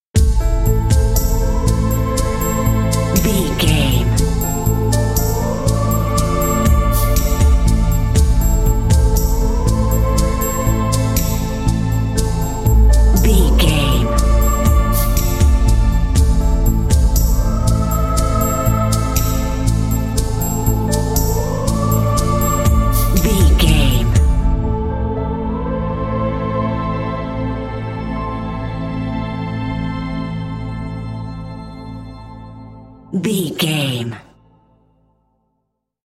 Aeolian/Minor
Slow
synthesiser
piano
percussion
drum machine
ominous
suspense
haunting
creepy